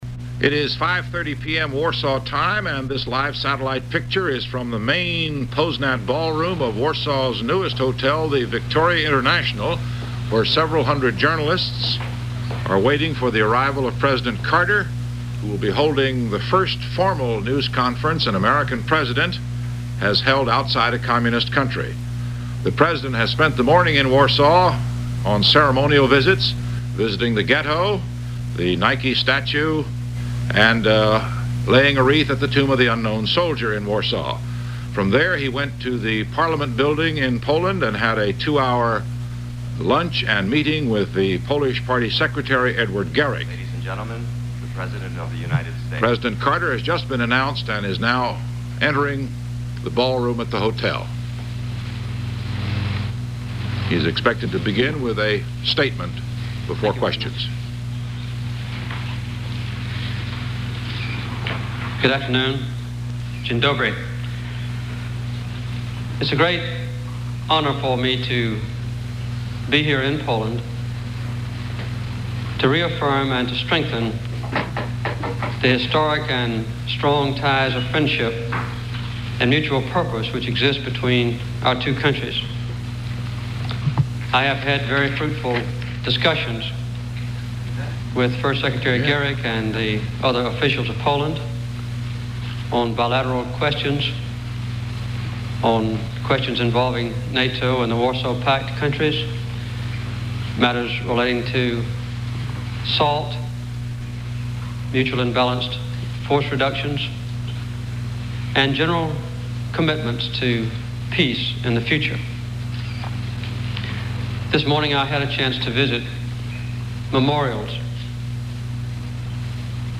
First press conference ever of a U.S. President from behind the Iron Curtain, from the Victoria Hotel in Warsaw, Poland
Carter answers questions about nuclear arms and biochemical warfare reduction, daily communications with Arab leaders, U.S. preference for a Palestinian homeland connected with Jordan, his Baptist faith and his hopes for more religious freedom in all countries, his hopes that Soviet influence on Poland will diminish, the Helsinki and Belgrade conferences on human rights, increasing human rights in Poland, the energy crisis in the world, but maily in the U.S., US-Polish economic intercourse, up to one billion dollards in 1978; the neutron bomb, his hopes that Poles can visit family members in the US and vice versa; includes post-interview comments on the state of Polish Jews. With reporter Roger Mudd.
Broadcast on CBS TV, December 30, 1977.